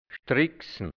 Mundart-Wörter | Mundart-Lexikon | deutsch-hianzisch | Redewendungen | Dialekt | Burgenland | Mundart-Suche: A Seite: 20